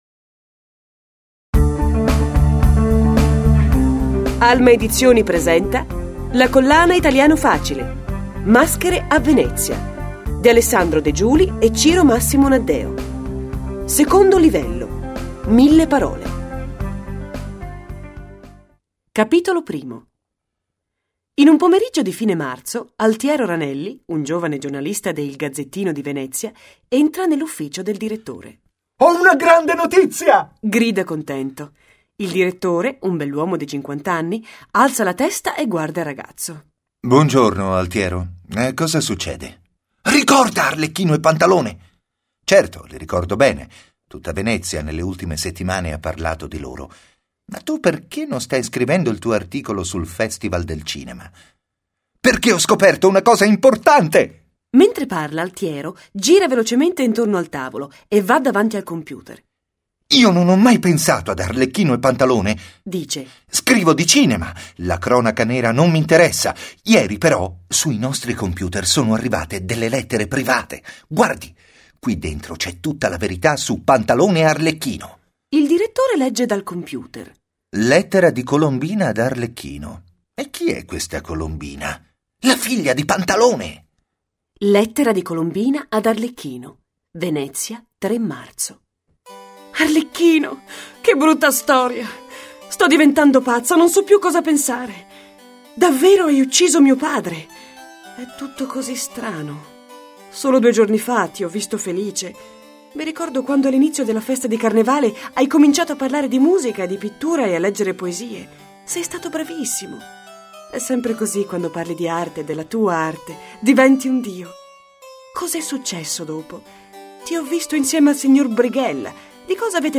Il volume fa parte della Nuova Serie della collana Italiano Facile, letture graduate per studenti stranieri con esercizi e versione audio del testo, con voci di attori professionisti ed effetti sonori realistici e coinvolgenti.